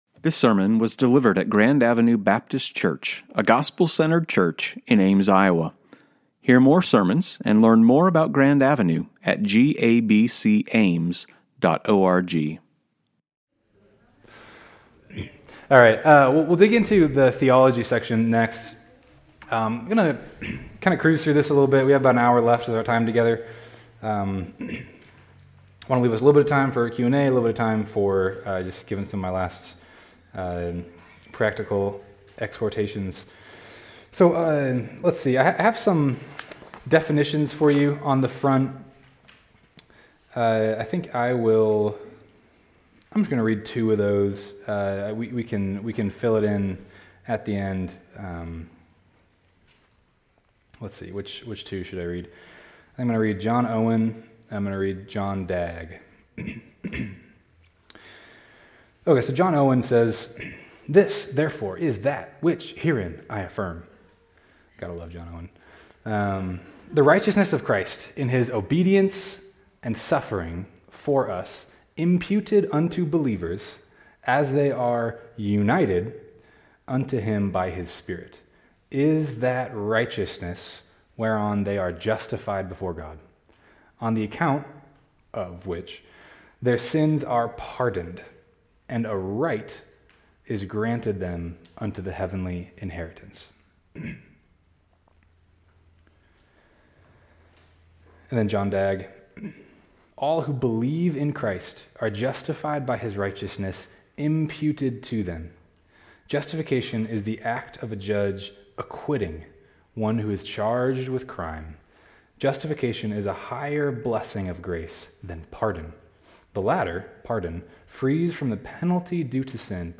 One Day Seminar